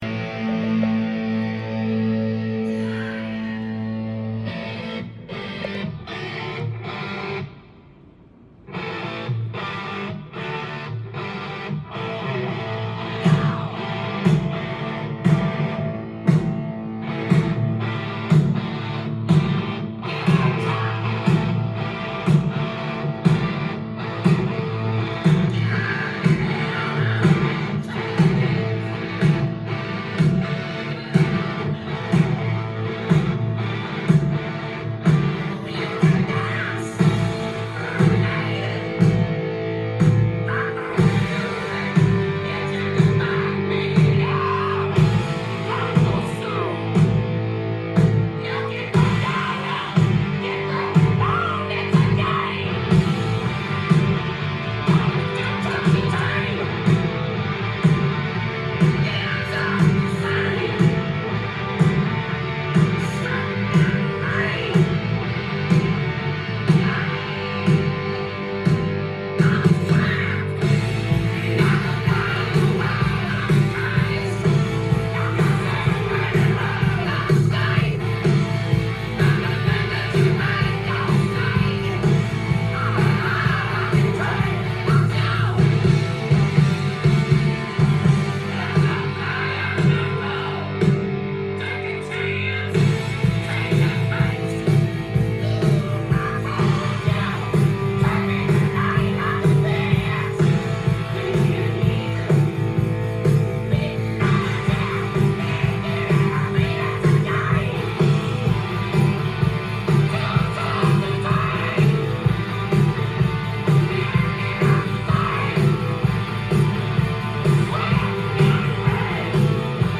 ジャンル：HARD-ROCK
店頭で録音した音源の為、多少の外部音や音質の悪さはございますが、サンプルとしてご視聴ください。
セルフ・プロデュースによる剥き出しのサウンドは、まるでガレージで演奏を聴いているかのような凄まじい臨場感です。